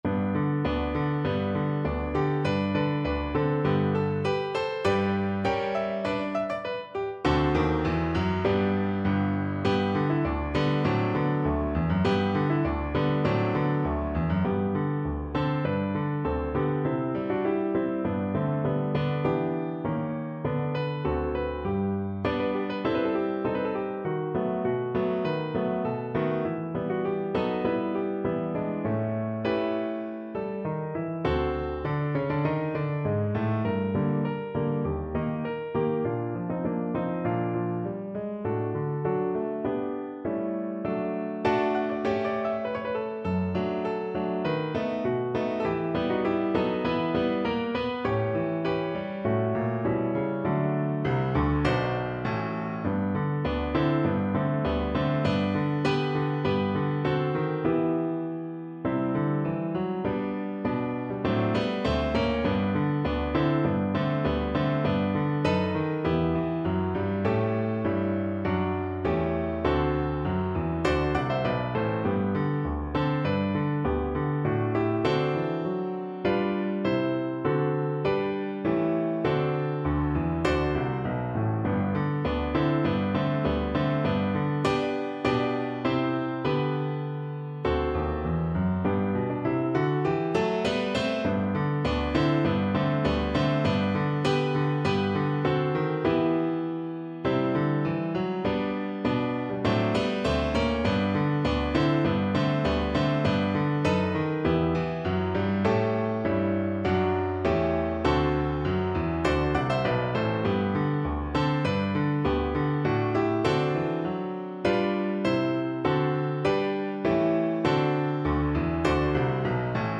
No parts available for this pieces as it is for solo piano.
Tempo di Marcia
2/4 (View more 2/4 Music)
Piano  (View more Intermediate Piano Music)